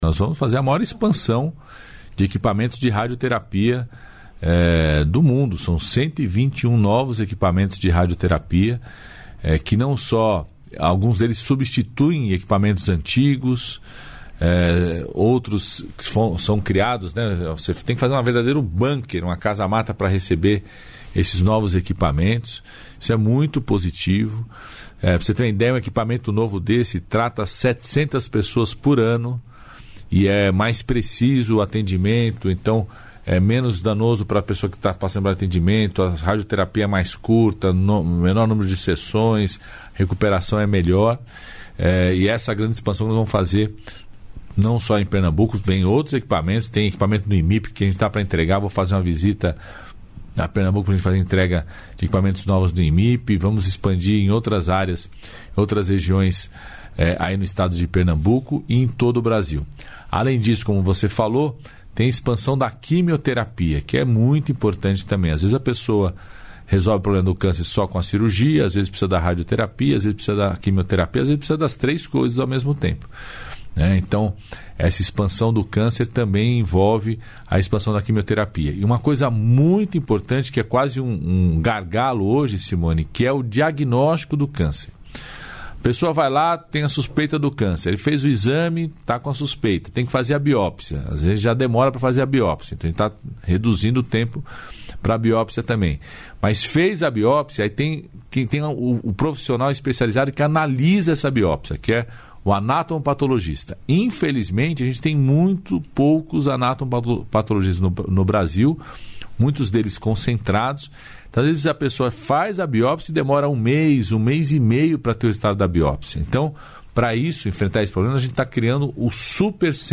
Trecho da participação do ministro da Saúde, Alexandre Padilha, no programa "Bom Dia, Ministro" desta quarta-feira (25), nos estúdios da EBC em Brasília (DF).